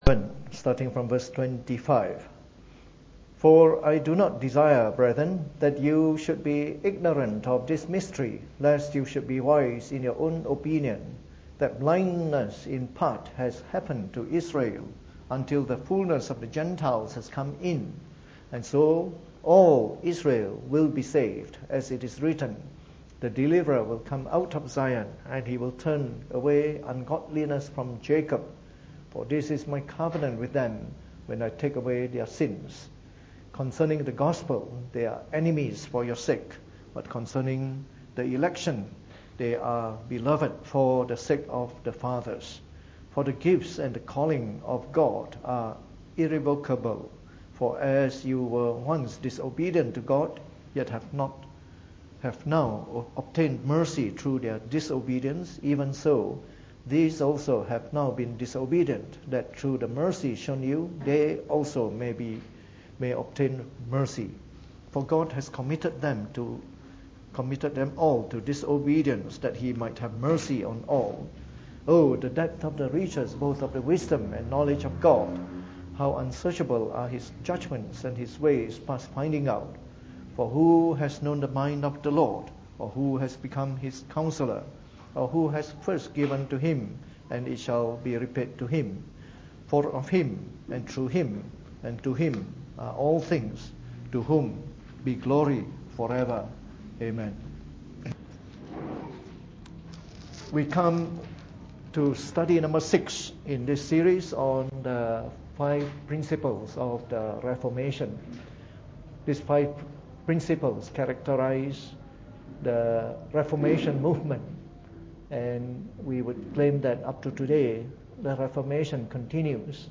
Preached on the 23rd of November 2016 during the Bible Study, from our series on the Five Principles of the Reformed Faith.